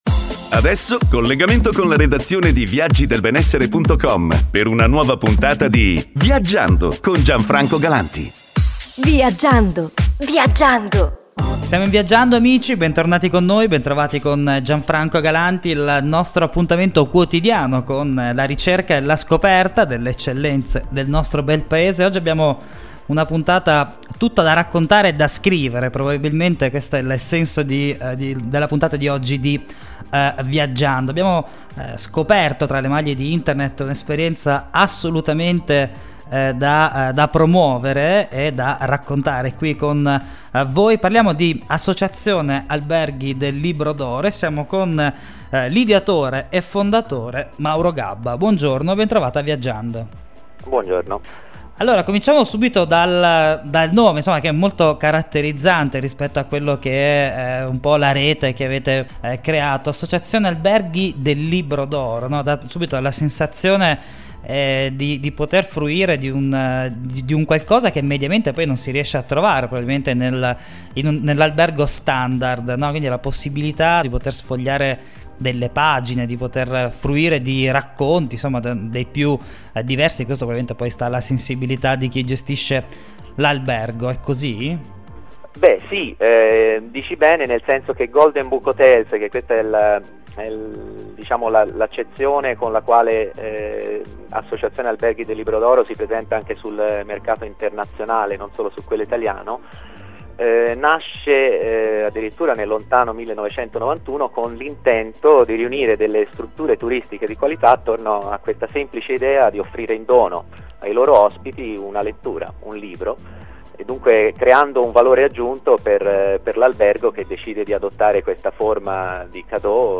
Intervista Radio7
viaggiando_intervista.mp3